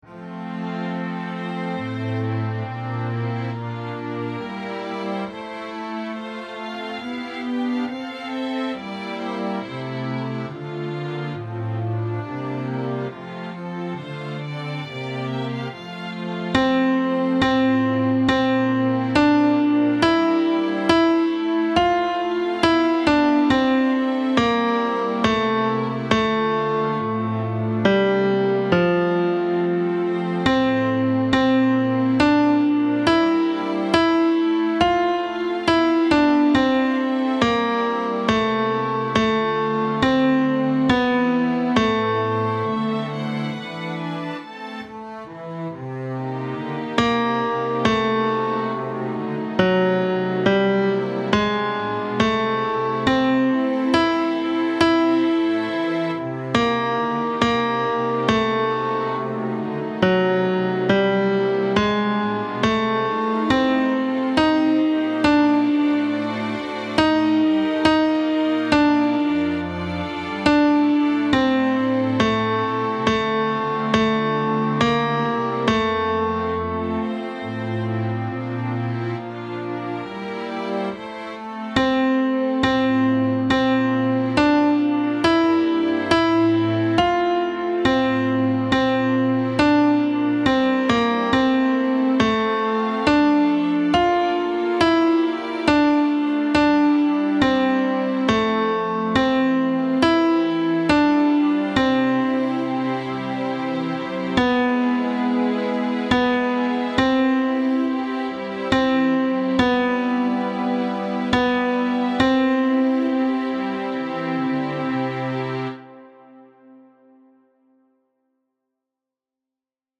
Tenor II
Mp3 Música